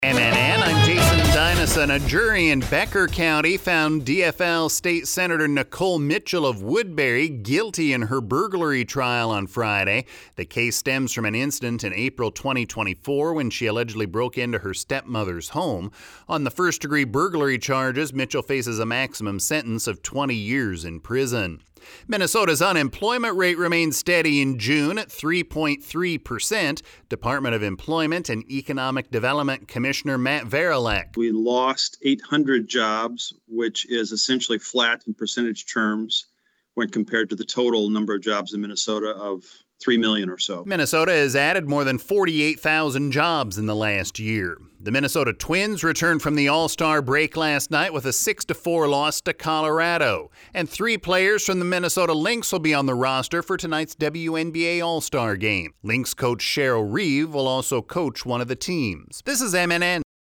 LISTEN TO THE LATEST MNN NEWSCAST